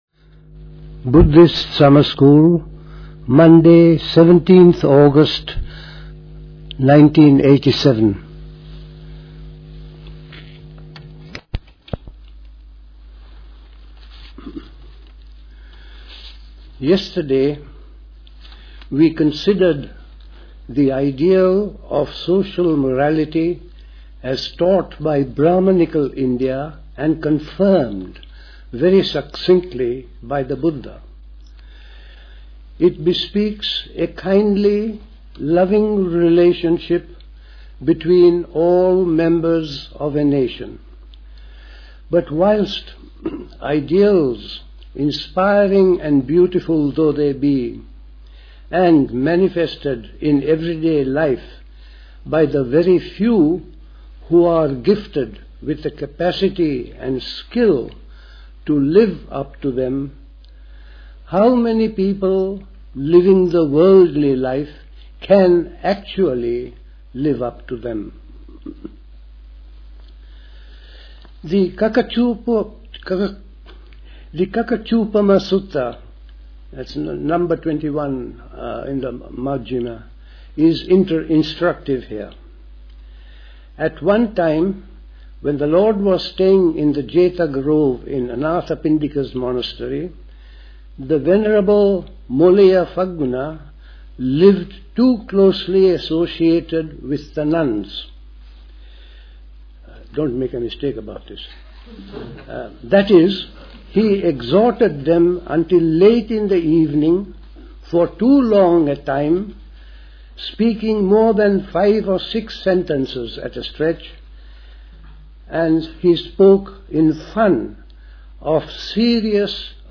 Recorded at the 1987 Buddhist Summer School.